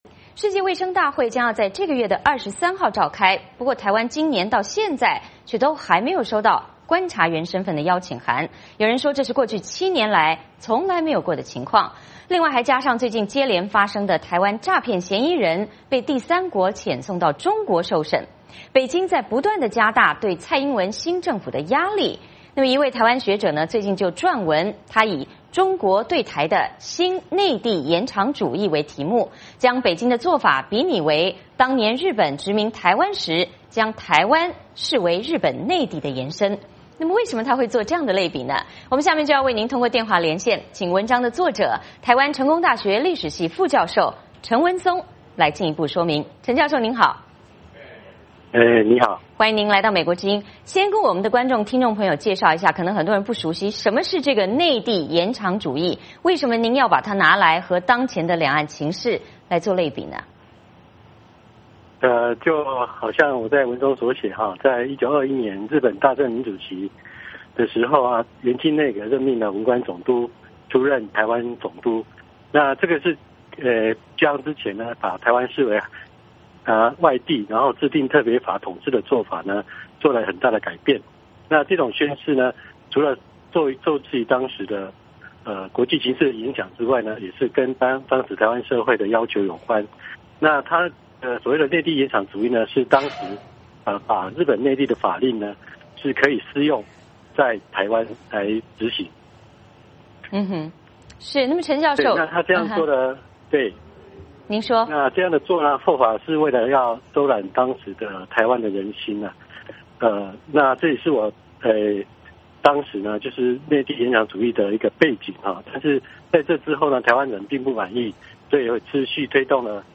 我们下面通过SKYPE连线